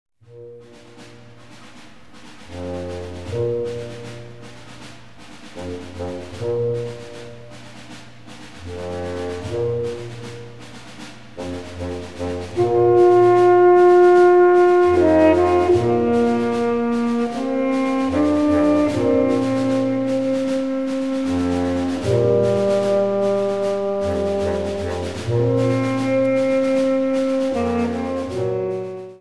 Sous-catégorie Musique de concert
Instrumentation Ha (orchestre d'harmonie)
2 Bolero 3:10